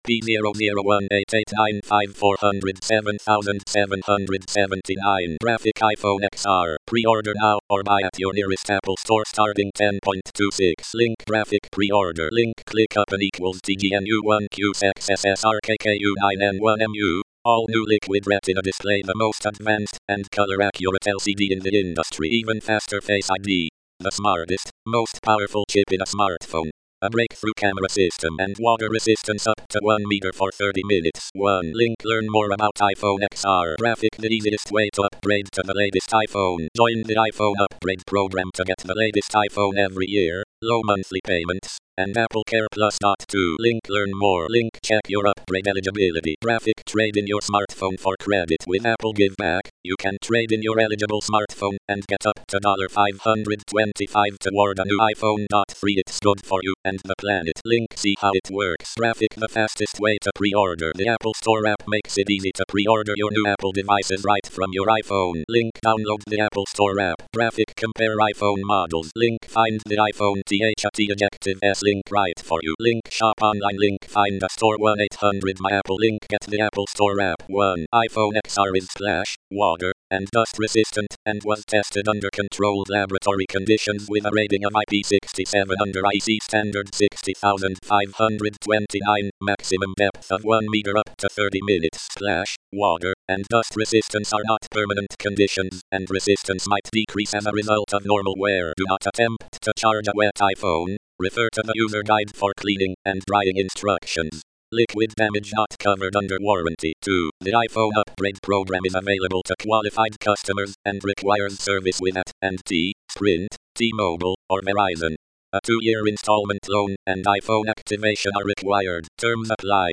Text to voice recording and transcript for hearing impaired.